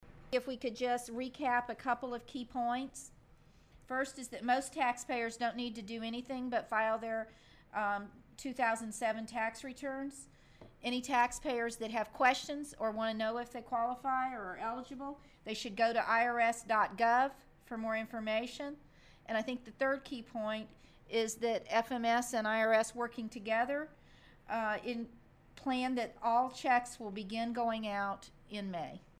summary of the key points of the economic stimulus payments by IRS acting Commissioner Linda Stiff
economic_stimulus_payments_key_points_soundbite.mp3